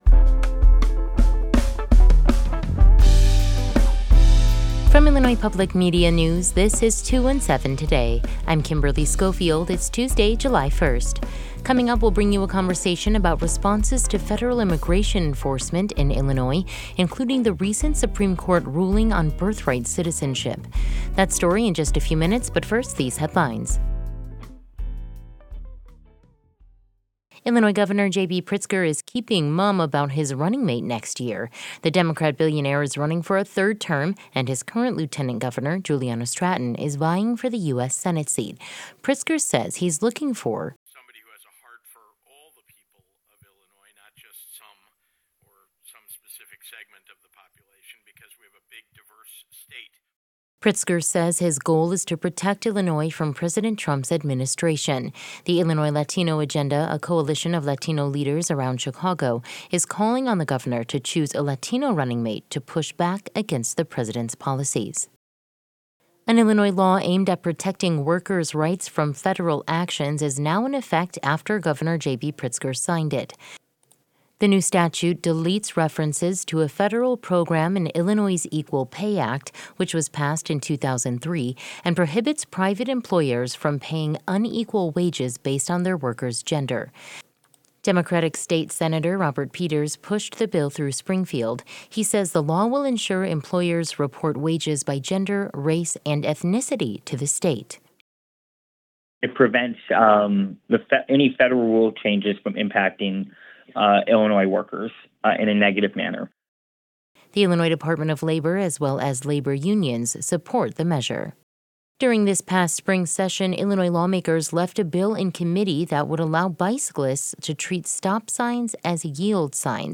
In today’s deep dive, we'll bring you a conversation about responses to federal immigration enforcement in Illinois including the recent Supreme Court ruling on birthright citizenship.